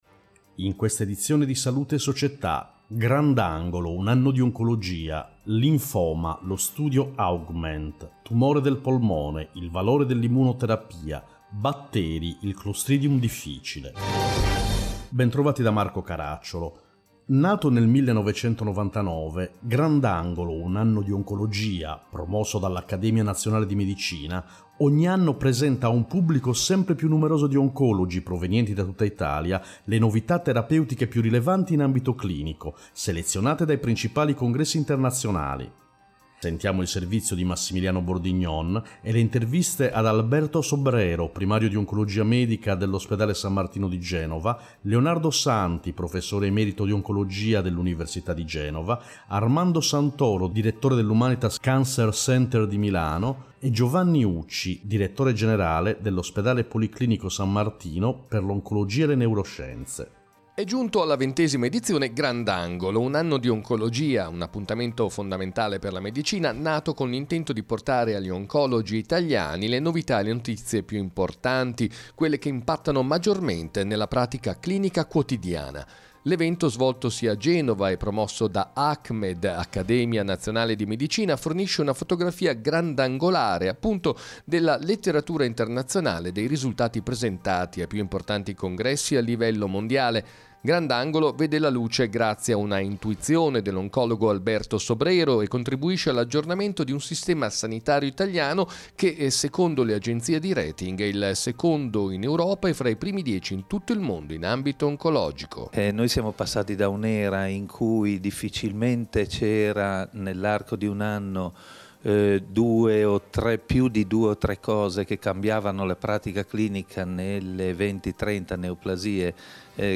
In questa edizione: 1. Grandangolo, Un anno di oncologia 2. Linfoma, Lo studio Augment 3. Tumore al polmone, Valore dell’immunoterapia 4. Batteri, Clostridium difficile Interviste